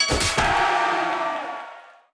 Media:RA_El_Tigre_Dep_002.wav 部署音效 dep 局内选择该超级单位的音效